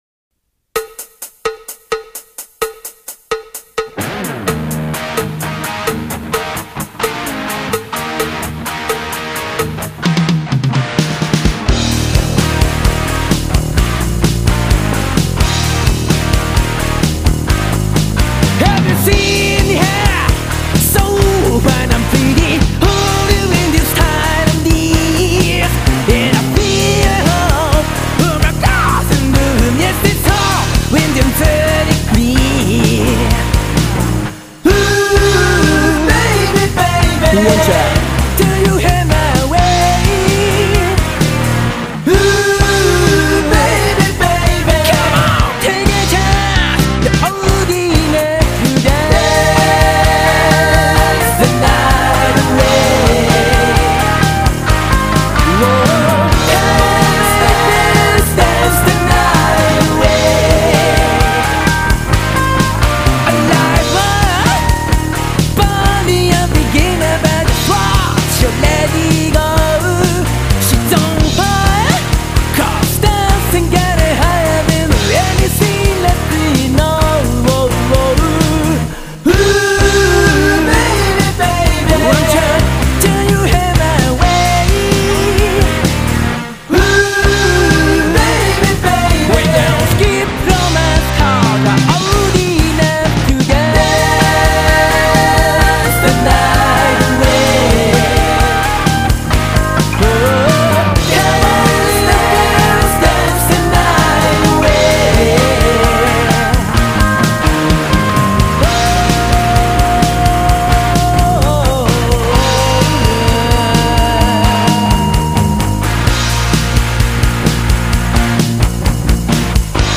しかも、CD-R 配布ライブ！